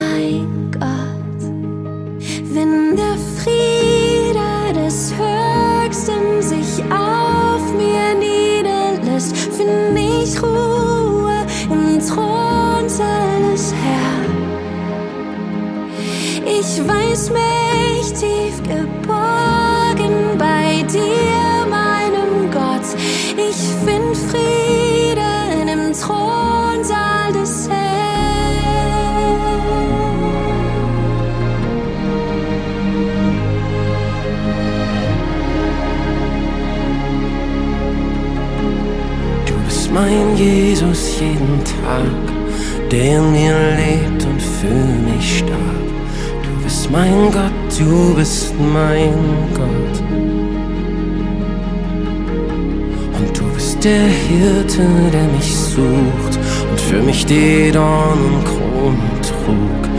Lobpreis deutsch